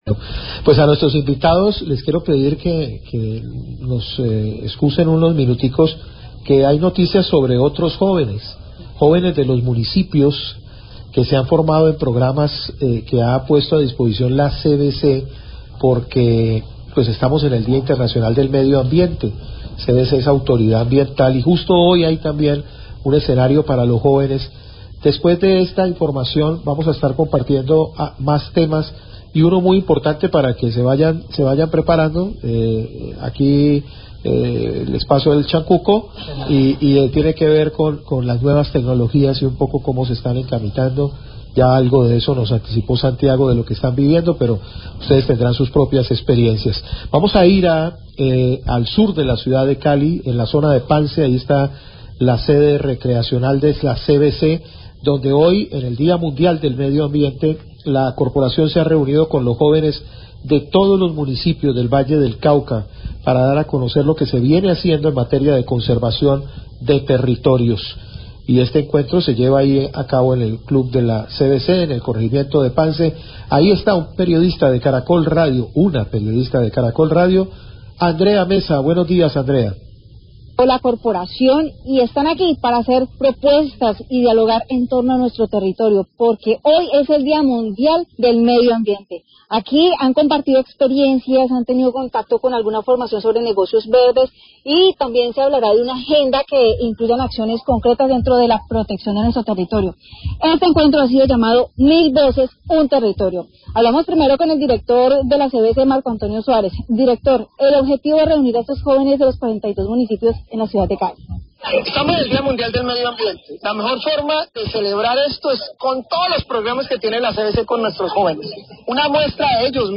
Dir. general CVC habla del encuentro con jóvenes denominado 'Mil Veces Un Territorio'
Radio